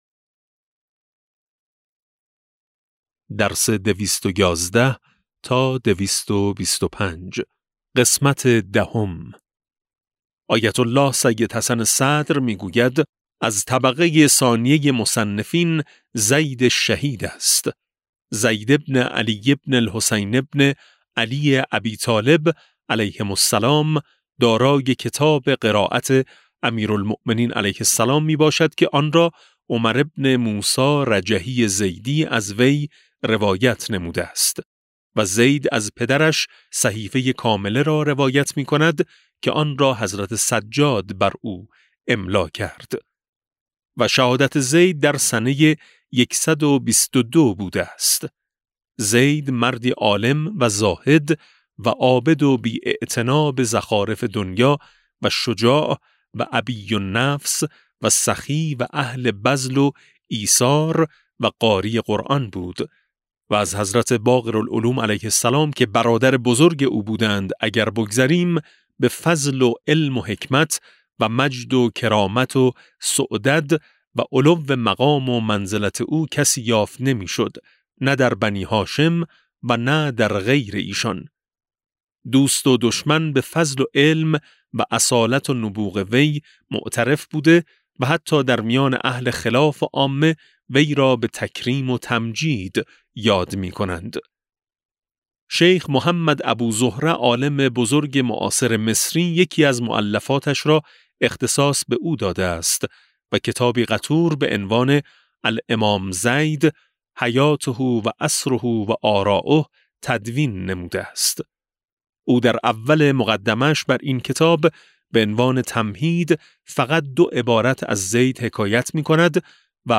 کتاب صوتی امام شناسی ج15 - جلسه10